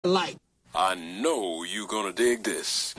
Richard Pryor Dialogue